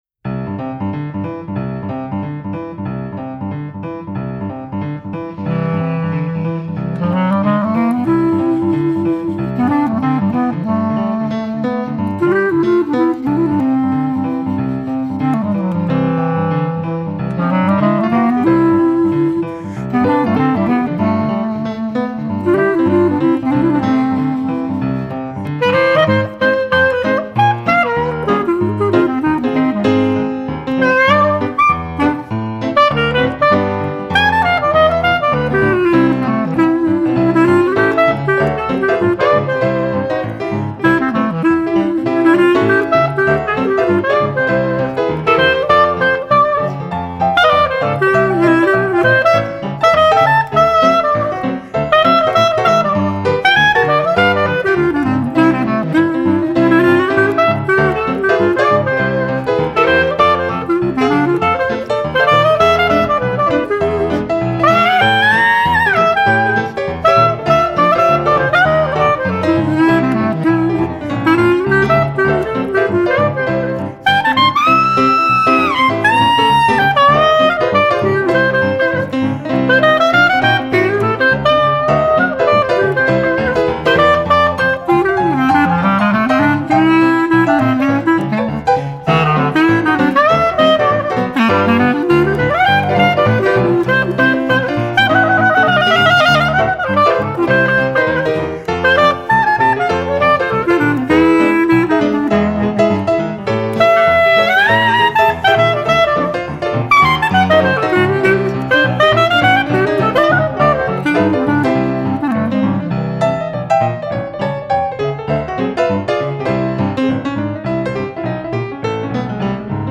clarinette
trombone
piano